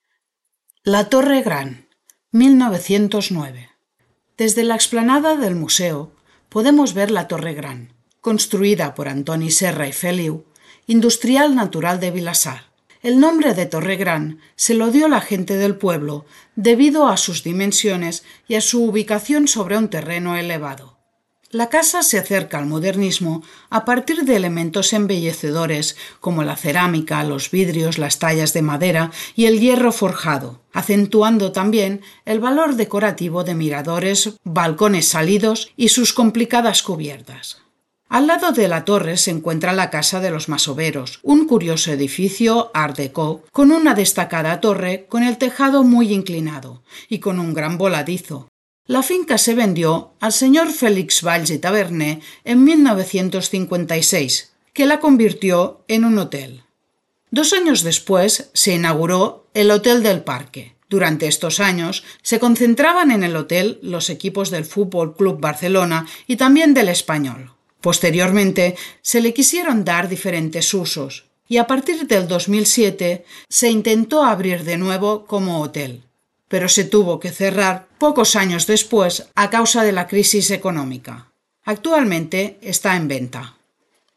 • RUTA MODERNISTA AUDIOGUIADA